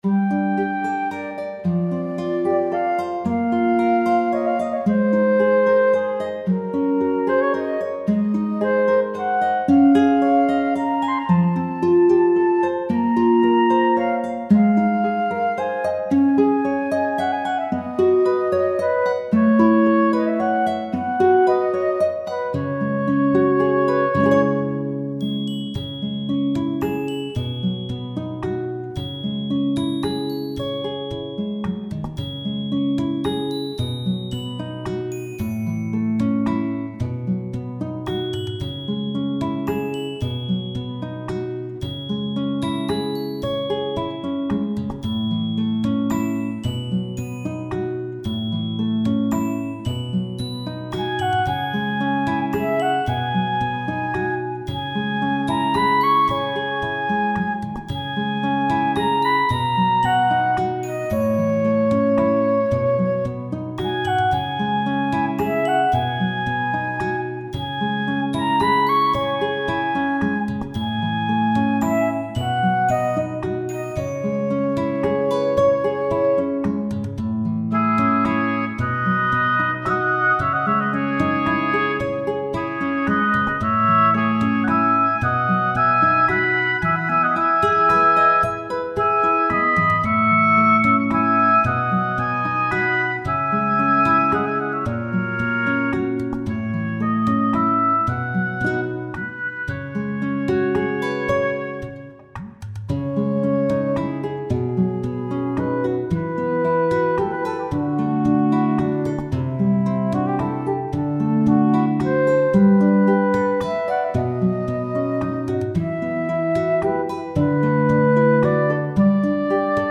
ファンタジー系フリーBGM｜ゲーム・動画・TRPGなどに！
スタッカートのピアノ伴奏は雨属性だと思う（諸説あり）